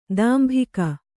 ♪ dāmbhika